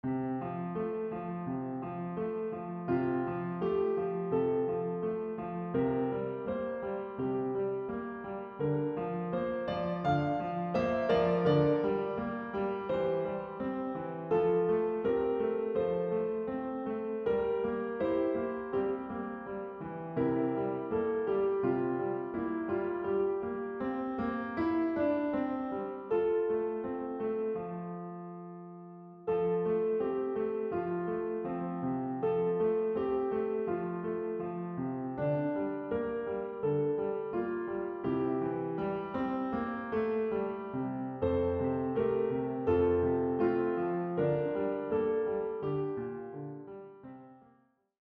presents a happy start to the day.